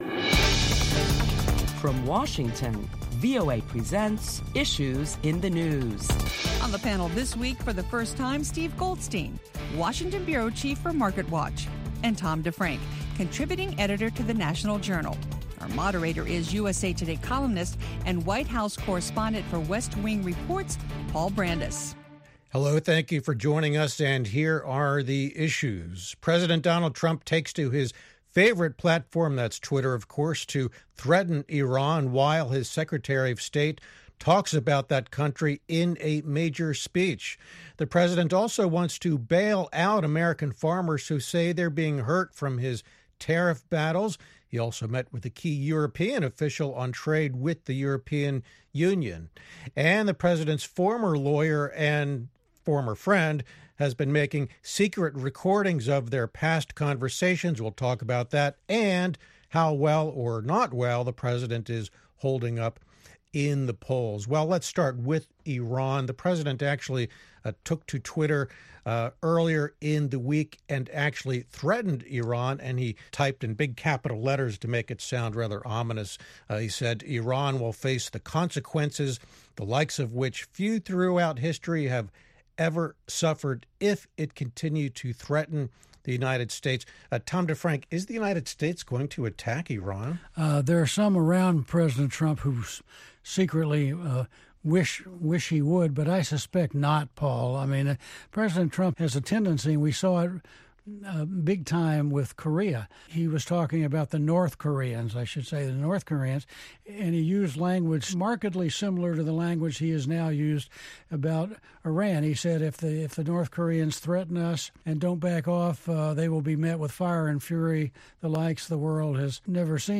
Top Washington correspondents discuss the week’s headlines including the latest saber rattling tweets from President Donald Trump and Iranian President Hasan Rouhani.